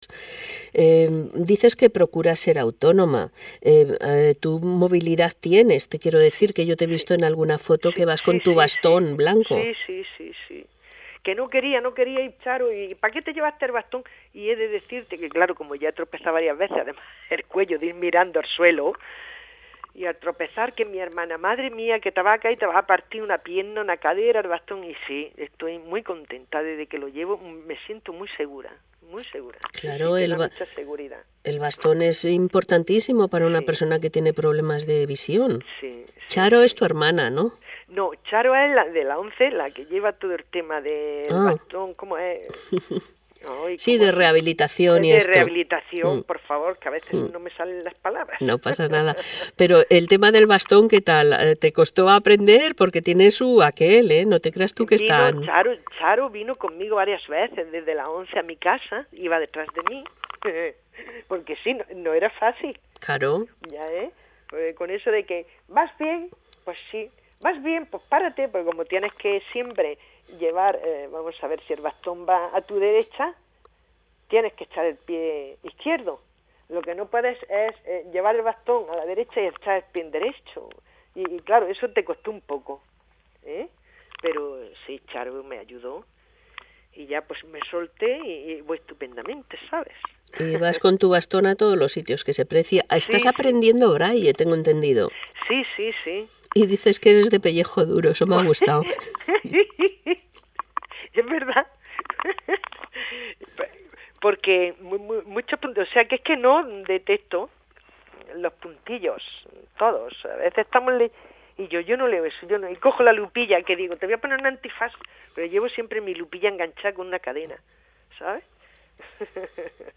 Fragmento de conversación